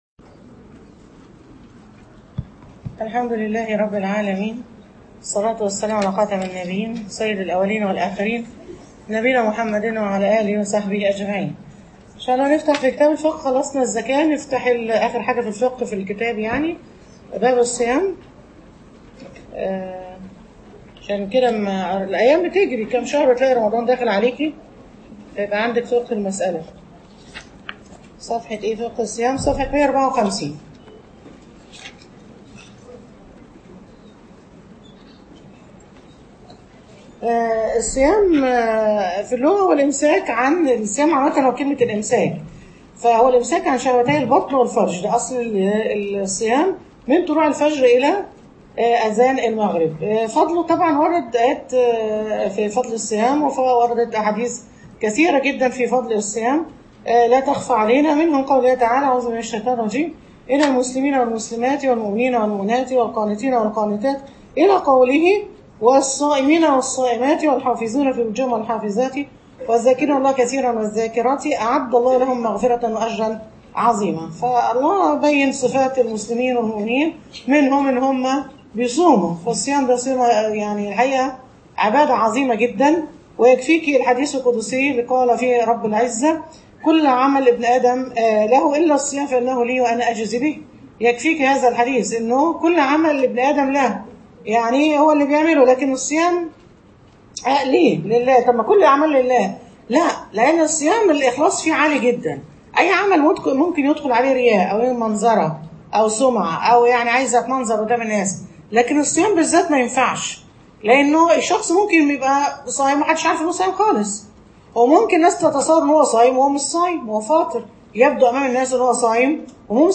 فقه الصيام_المحاضرة الأولى